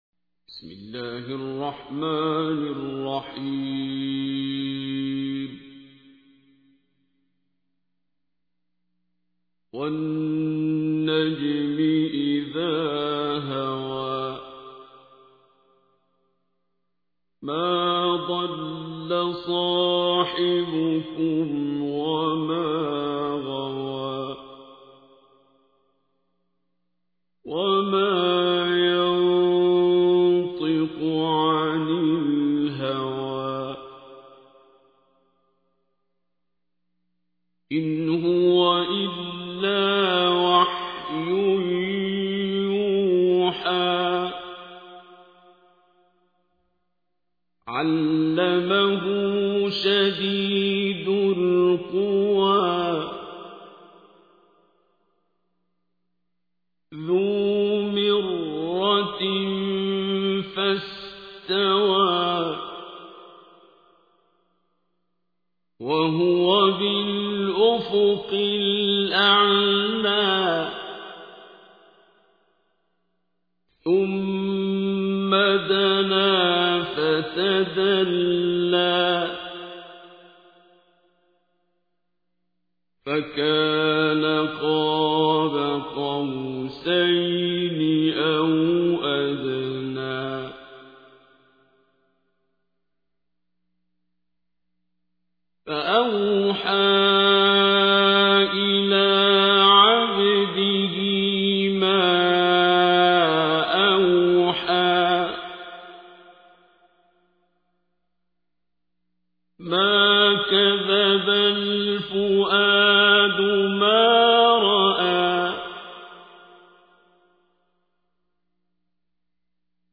تحميل : 53. سورة النجم / القارئ عبد الباسط عبد الصمد / القرآن الكريم / موقع يا حسين